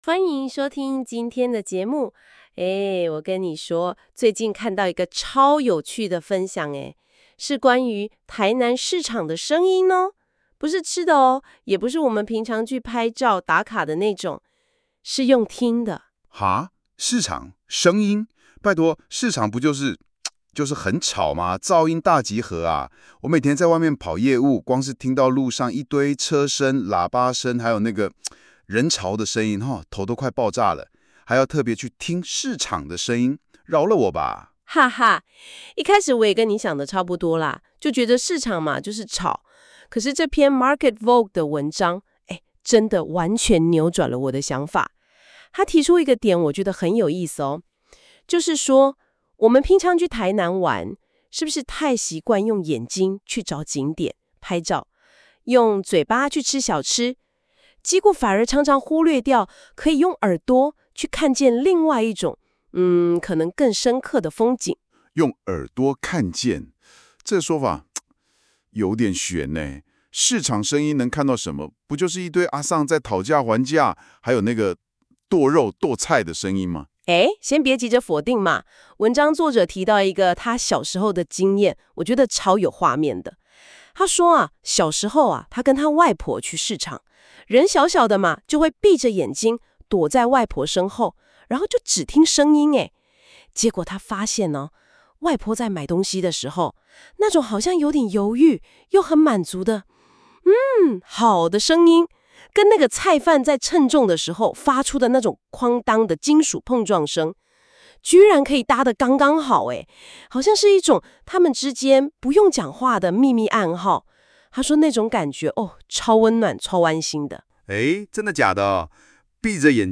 市場的聲音地景（一場衝擊你感官的市井漫遊）